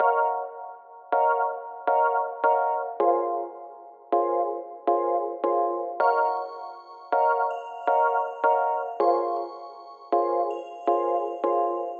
plugg 160bpm (mexikodro type).wav at 32ed3054e8f0d31248a29e788f53465e3ccbe498